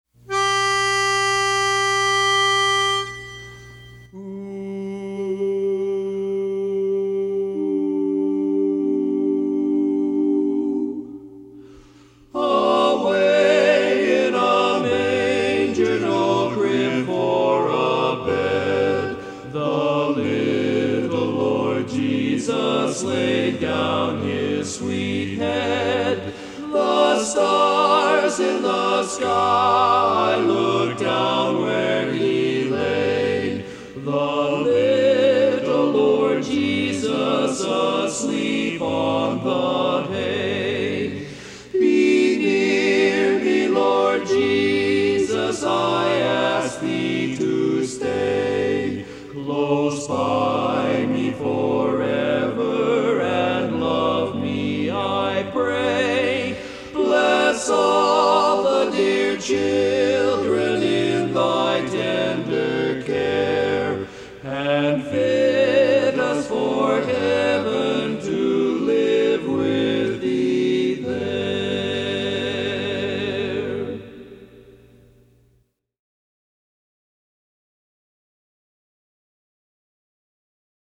Barbershop
Lead